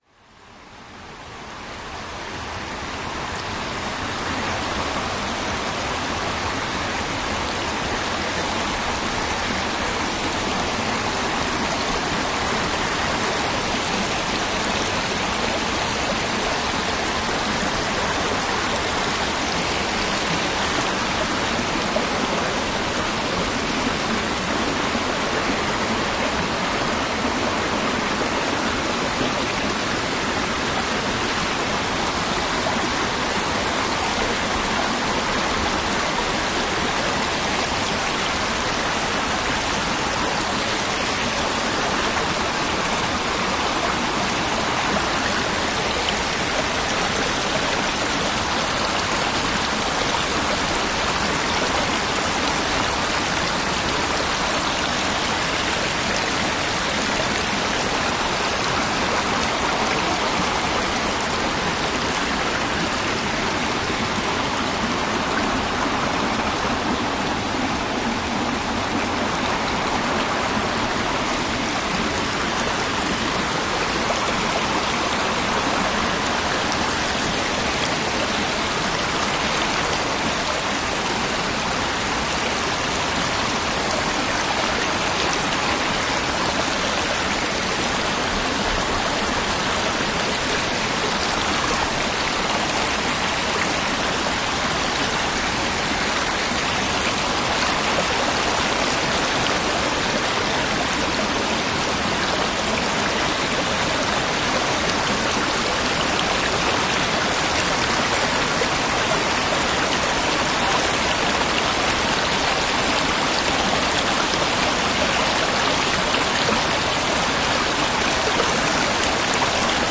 描述：一个密集的森林溪流的循环记录。 在瑞典Nerikes Kil的Rosendal，使用Zoom H4n在夏日午后录制。
Tag: 自然 森林